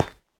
sounds / block / iron / step3.ogg